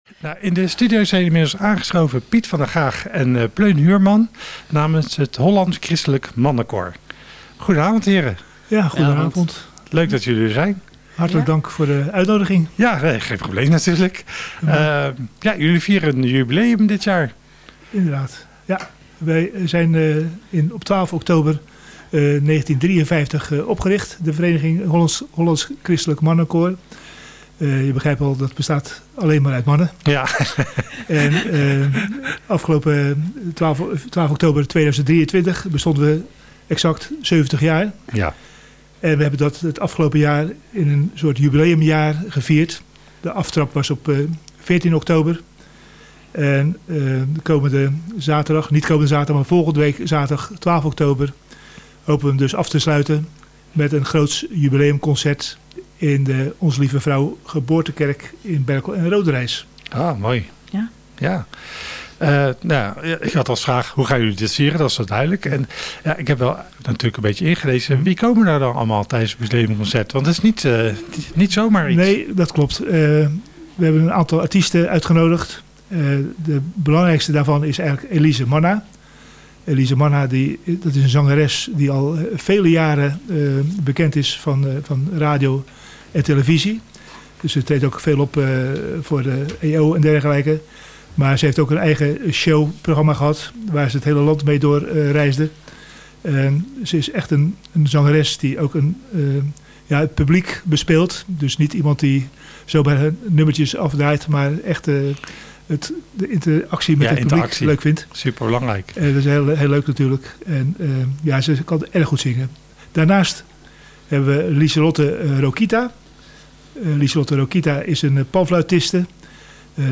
die namens het koor in de radiostudio aanwezig waren